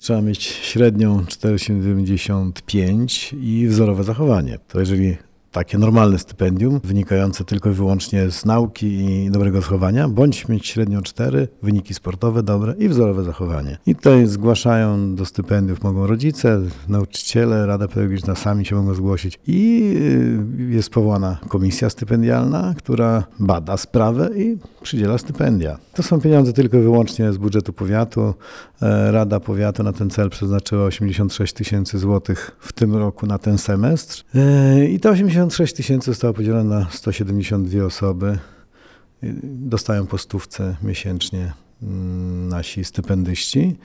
-Miesięcznie nagrodzeni otrzymywać będą 100 złotych, mówił w Radiu 5 Marek Chojnowski – starosta powiatu ełckiego.